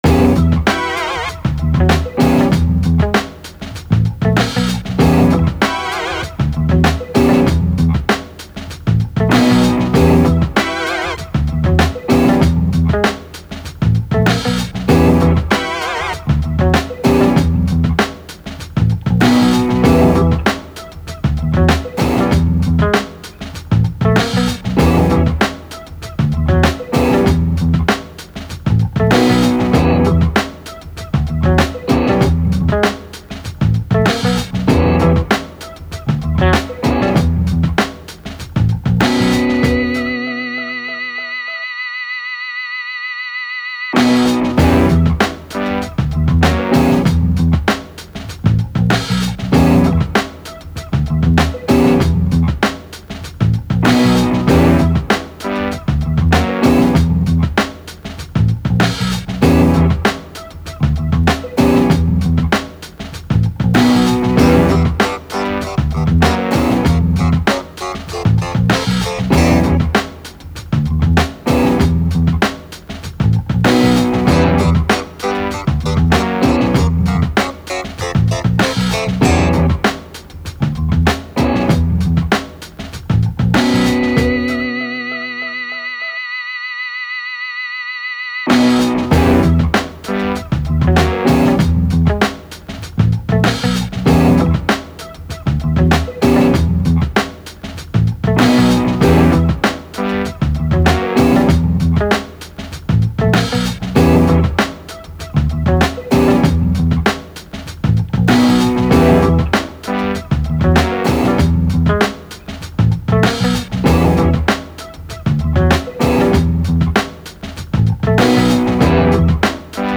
More fully loaded leftfield funk, lay back then jump up.
Edgy funk groove with attitude.